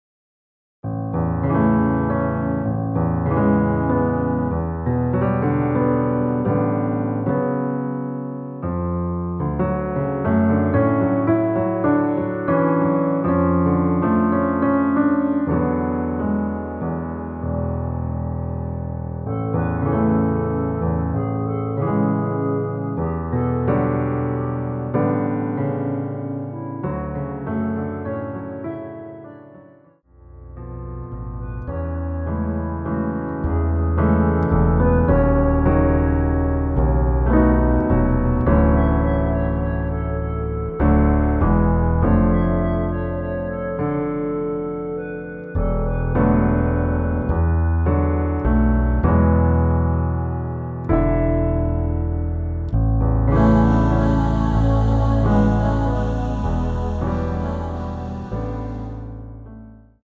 원키에서(-5)내린 멜로디 포함된 MR입니다.(미리듣기 확인)
앞부분30초, 뒷부분30초씩 편집해서 올려 드리고 있습니다.
중간에 음이 끈어지고 다시 나오는 이유는